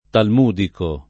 [ talm 2 diko ]